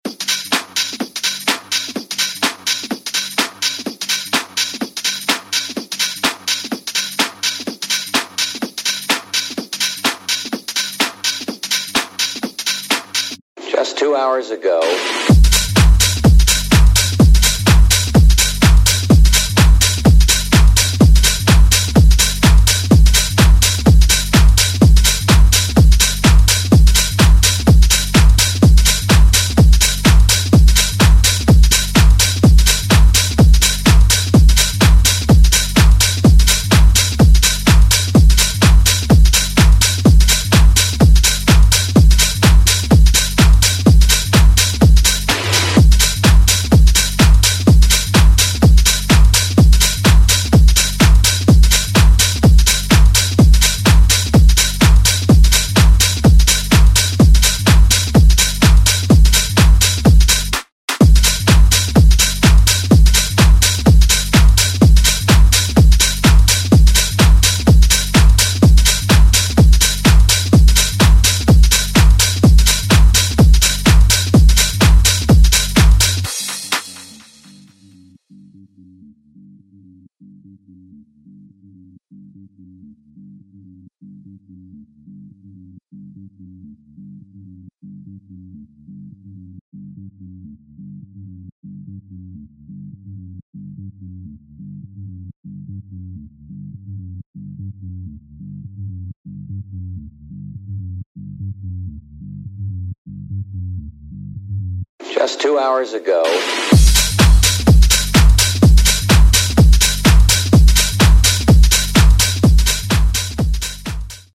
a four track party-starter of an EP.
drum-laden
raw energy
filtered disco flavour
funky feels
a bass-heavy beat tool